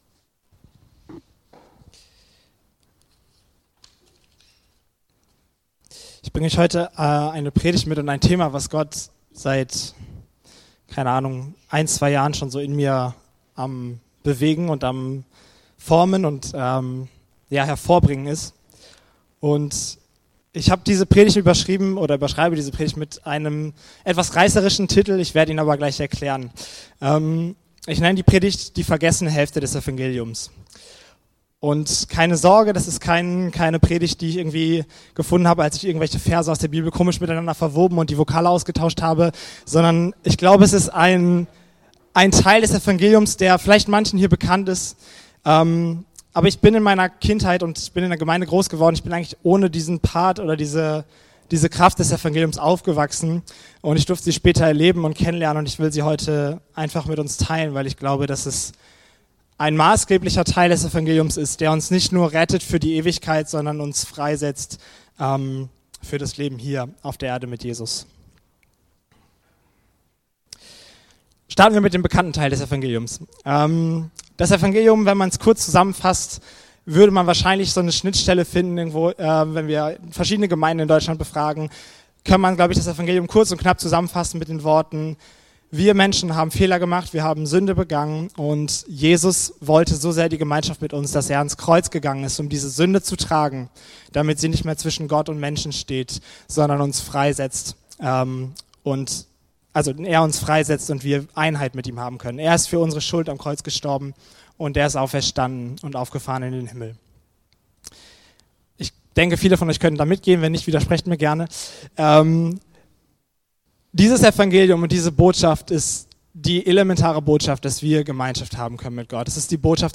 In seiner Predigt vom 8. März 2026 über Matthäus 6,14-15